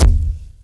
mortarbd.wav